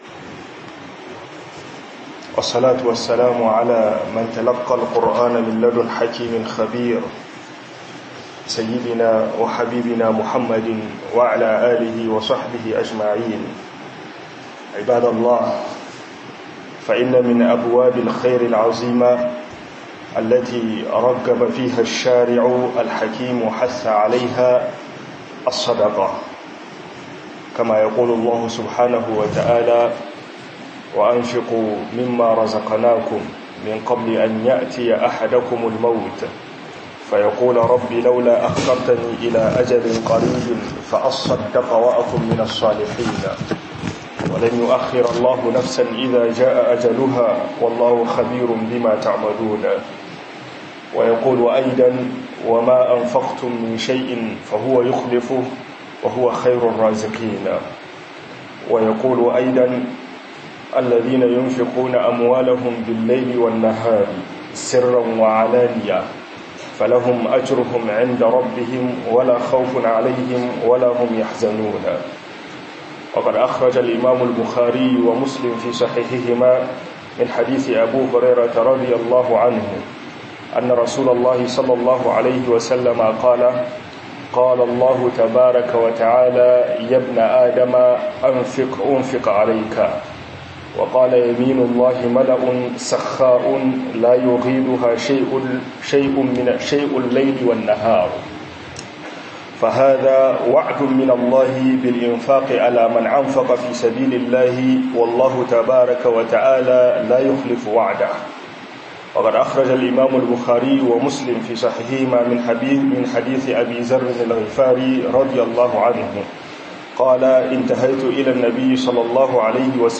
Yin SADAKA FI SABILILAHI - HUDUBA